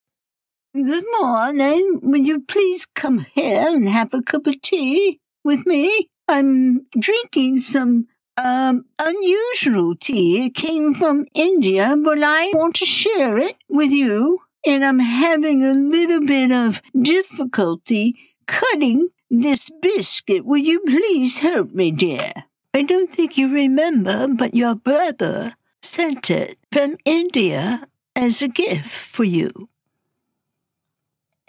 Narrations are recorded with a home studio-quality MSB Mic, providing consistent and reliable performance.
British Dame at Teatime